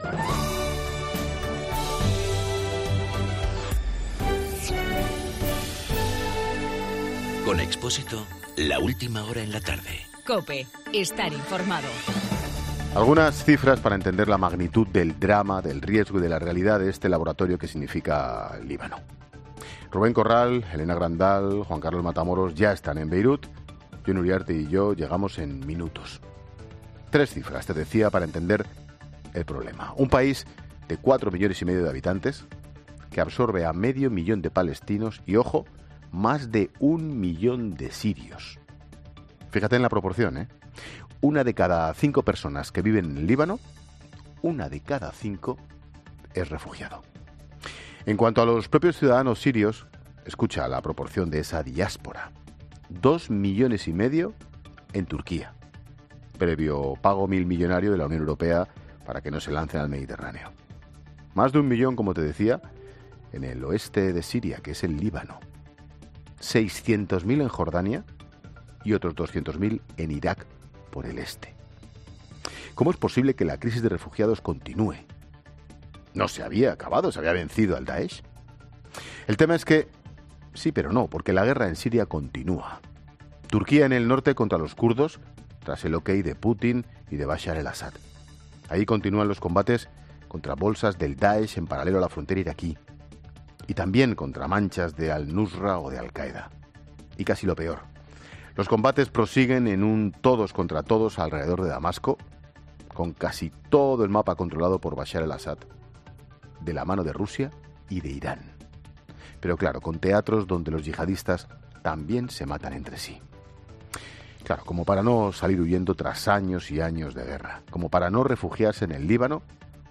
AUDIO: El comentario de Ángel Expósito desde el Líbano.